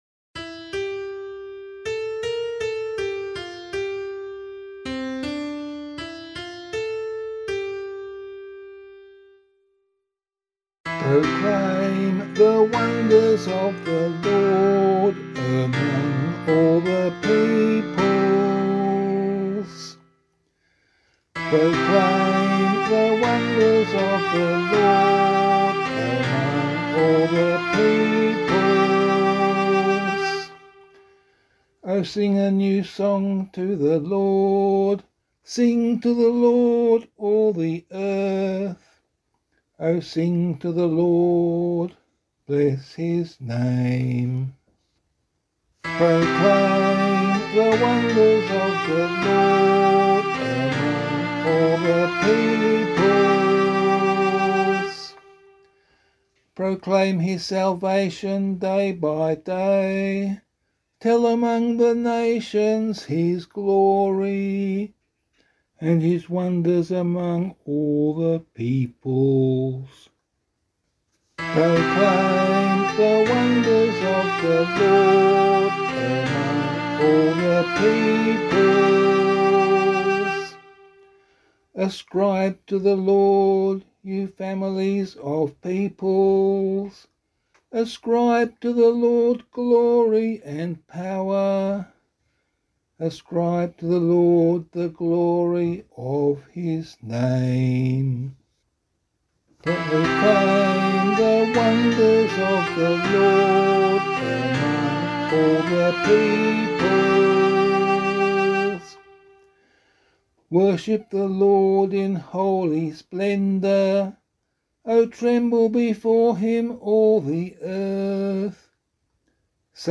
036 Ordinary Time 2 Psalm C [APC - LiturgyShare + Meinrad 7] - vocal.mp3